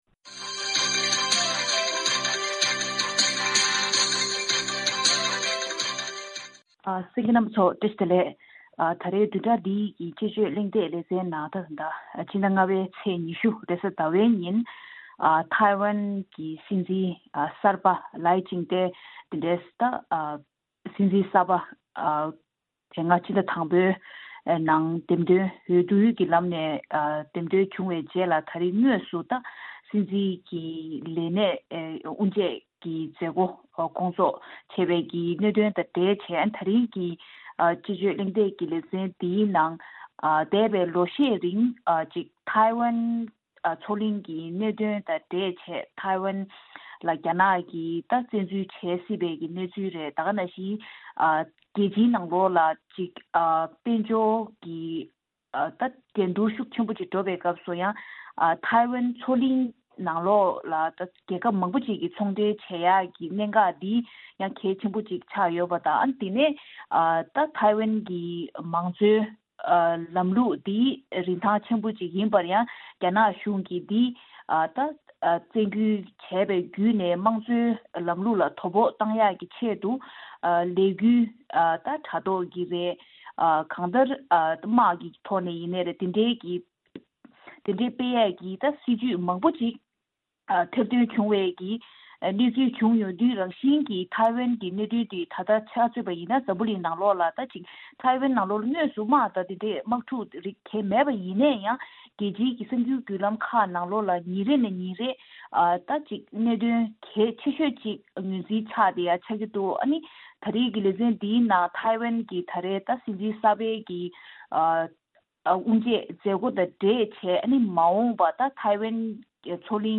དཔྱད་གླེང་ཞུས་པའི་ལས་རིམ།